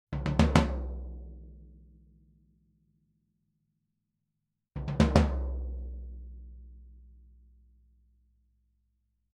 Das Standtom wurde wie im folgenden Bild zu sehen aufgebaut und mikrofoniert - zum Einsatz kamen zwei AKG C414B-ULS in einer ORTF-Anordnung, die direkt über ein Tascam DM24-Pult ohne weitere Bearbeitung mit 24 Bit in Samplitude aufgenommen wurden.
kurzer Roll- erst Vollgummi-, dann Air-Suspension-Füße
Diese Audio-Files sind absolut unbearbeitet belassen worden - also kein EQ, kein Limiter, kein Kompressor - nichts!